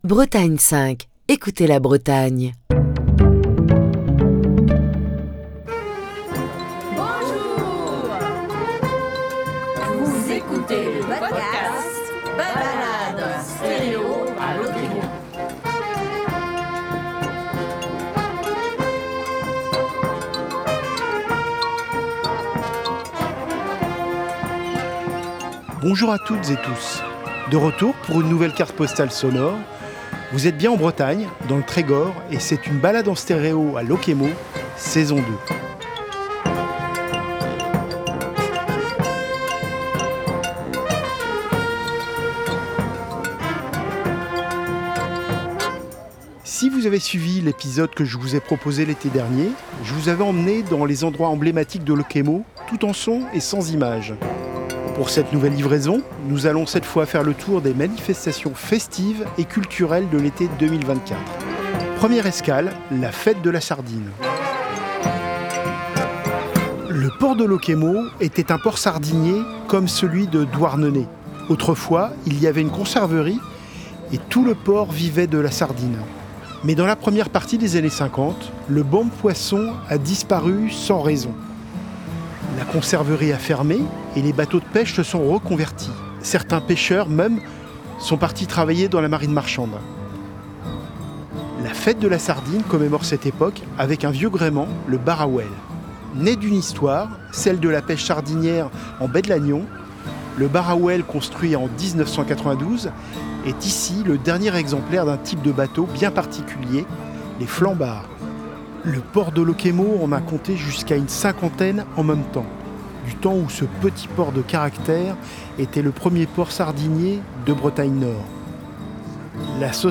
Ce sixième épisode de Balade en stéréo à Lokémo entame la série de reportages réalisés à Locquémeau durant l’été 2024...